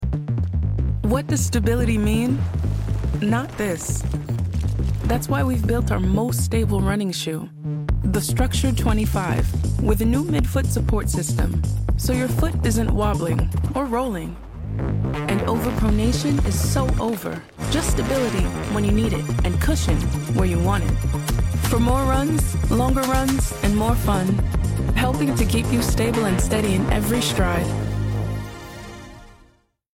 English (American)
Commercial, Accessible, Versatile, Friendly, Urban
Commercial